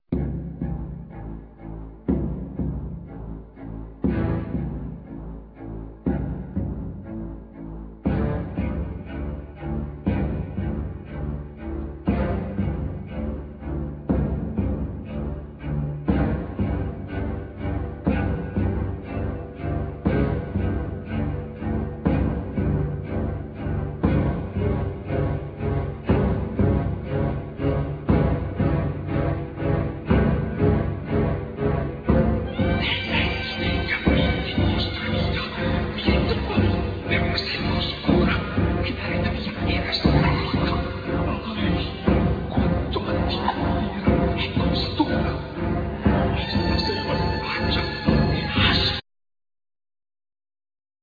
Gaida
Bass Clarinet,Soprano Saxophone
Flute
Arpa,Zanfonia
Viola da Gamba,Violin
MIDI instruments
Vocal
Tambor chamanico,zarb,Vocal
Viola d'amore con clavijas,Violin debolsillo,Vocal